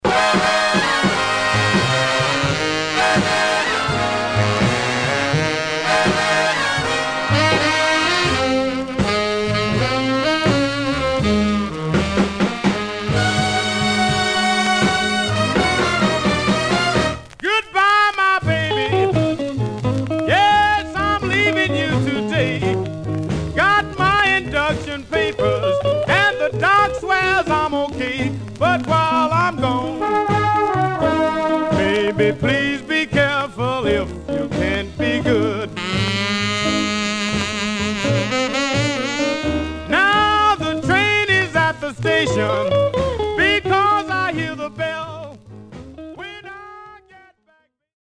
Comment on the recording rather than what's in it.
Condition Surface noise/wear Stereo/mono Mono